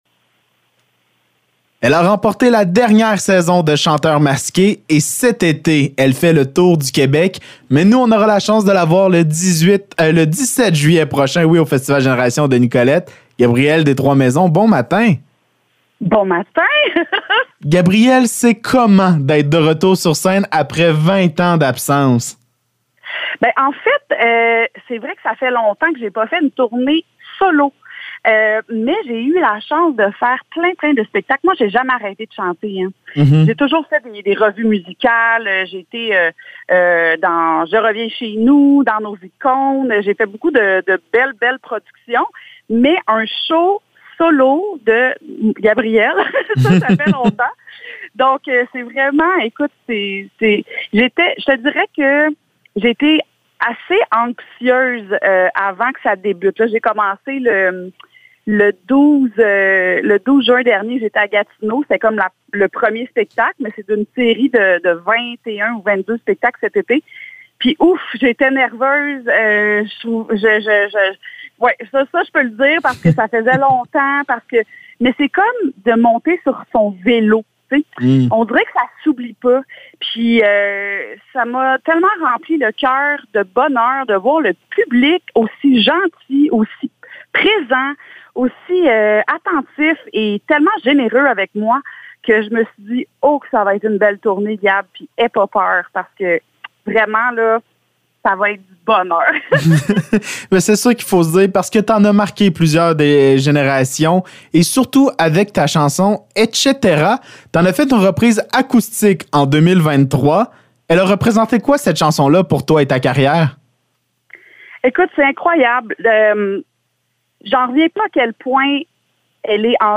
Entrevue avec Gabrielle Destroismaisons